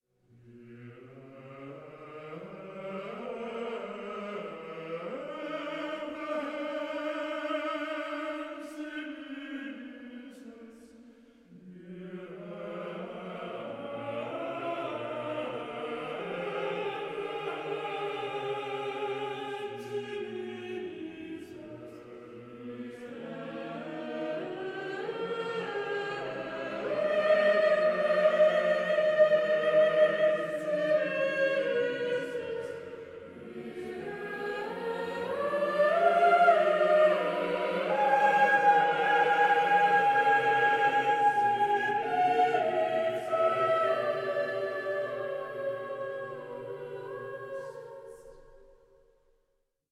at Wells Cathedral, Somerset, UK